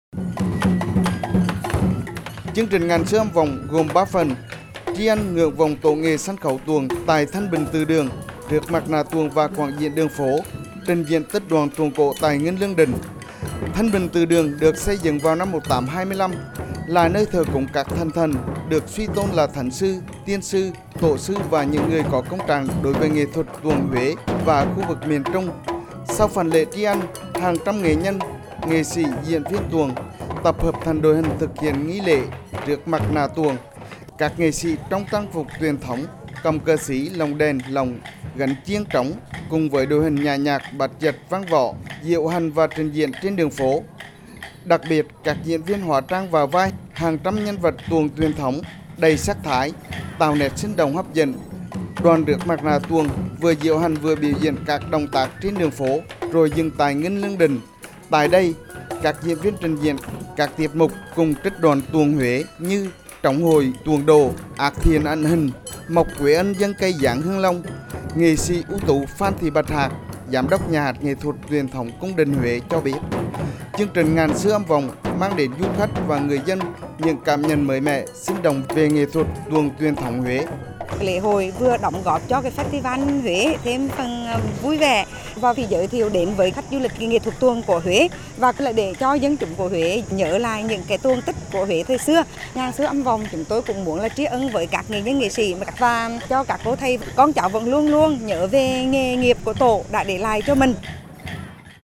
VOV1 - “Ngàn xưa âm vọng” là chương trình nghệ thuật tôn vinh di sản tuồng Huế trong khuôn khổ Festival Huế 2022. Hoạt động này mang đến một không khí lễ hội đường phố vui tươi, thu hút du khách và người dân.